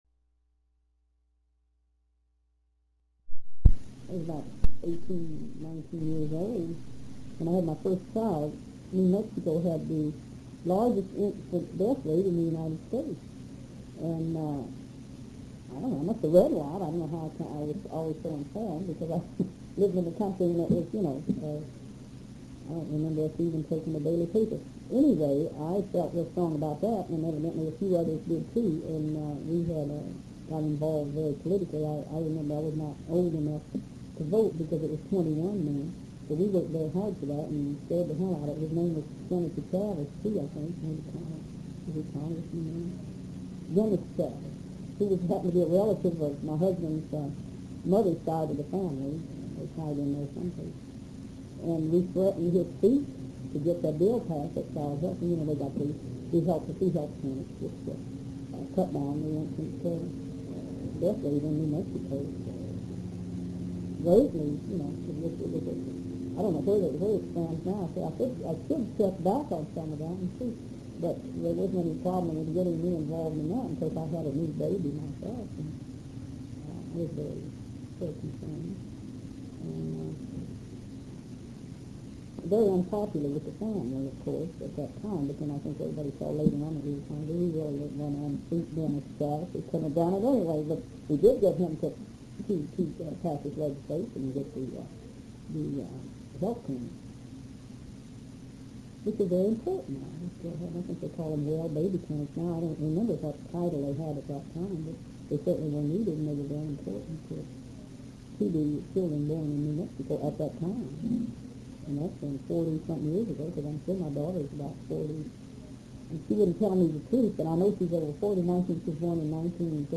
The Center was a hub of activity, and although the interviews were conducted in the quietest space that could be found, there was constant background noise and interruptions.
Note: the audio quality in this segment is fair.